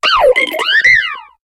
Cri de Pashmilla dans Pokémon HOME.